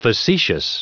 Prononciation du mot facetious en anglais (fichier audio)
Prononciation du mot : facetious